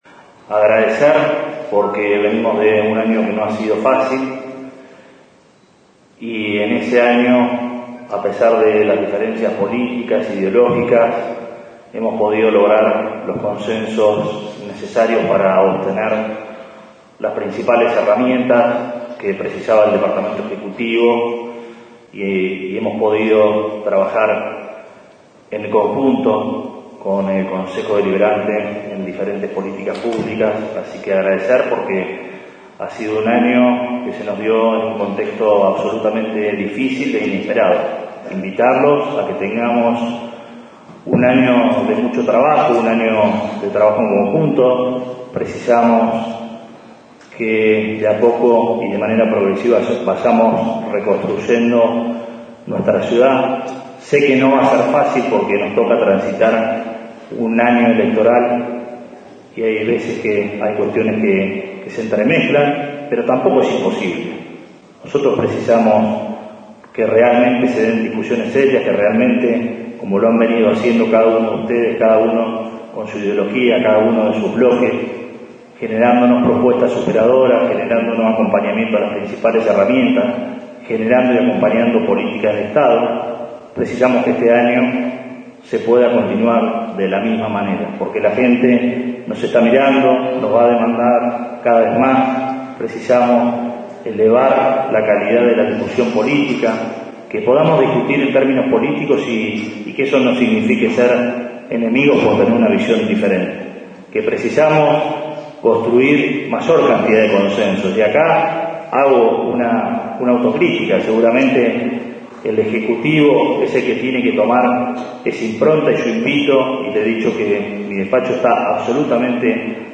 Con un discurso que se extendió por más de dos horas, el intendente Arturo Rojas abrió el período de sesiones ordinarias 2021, momento en el que ante los concejales y funcionarios que le dieron el marco al recinto del HCD, enumeró las gestiones llevadas adelante hasta el momento, área por área, al tiempo que reconoció un arduo trabajo durante la pandemia.